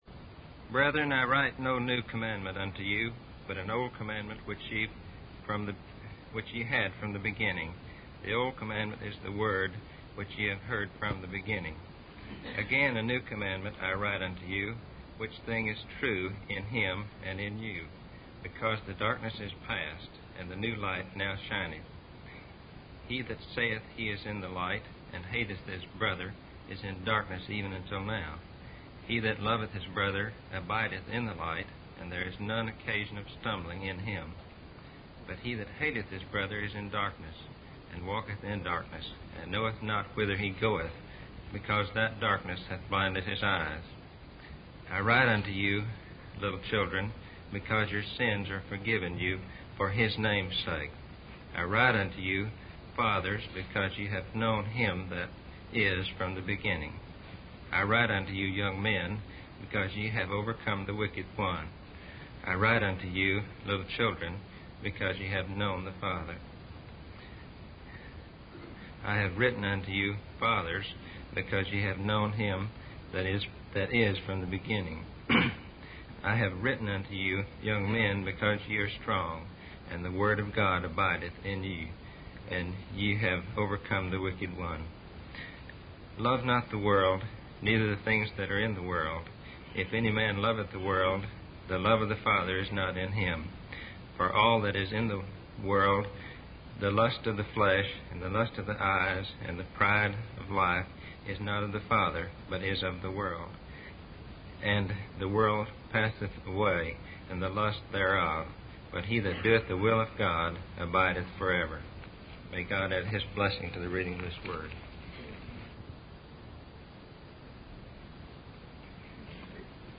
In this sermon, the preacher discusses the steps of temptation that led David to disgrace and how Satan uses these same steps to lead individuals away from God's will. The preacher emphasizes that Satan can only appeal to the fleshly, selfish, and prideful nature within us. The sermon also highlights the importance of understanding the nature of temptation and using the Word of God and the Holy Spirit to resist it.